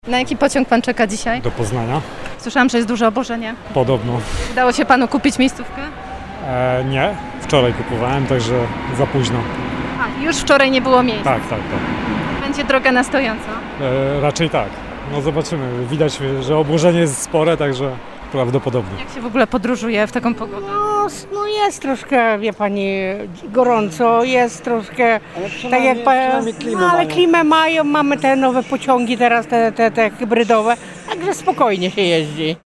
Ci, którzy chcą wykupić bilet na ostatnią chwilę, muszą liczyć się np. z brakiem miejsc siedzących – mówi jeden z pasażerów.
02-pasazerowie.mp3